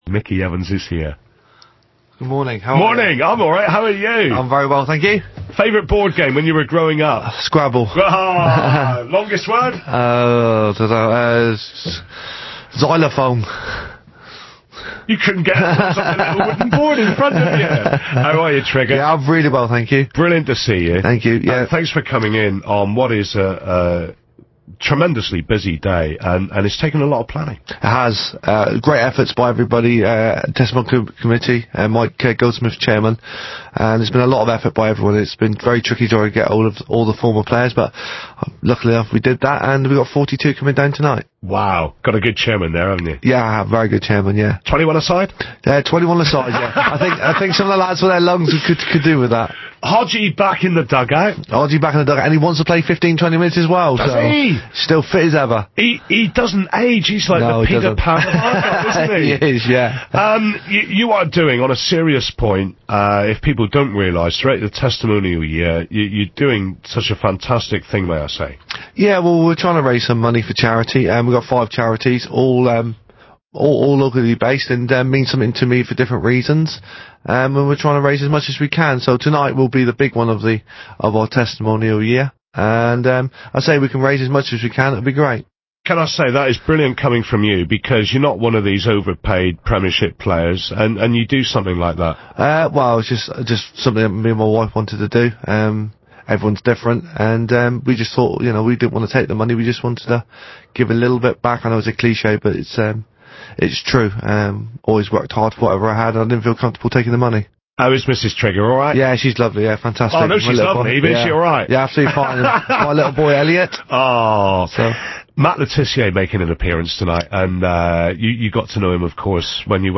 Interview and photos: